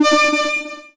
贡献 ） 分类:游戏音效 您不可以覆盖此文件。
se_heal.mp3